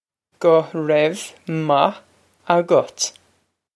guh rev mah a-gut
This is an approximate phonetic pronunciation of the phrase.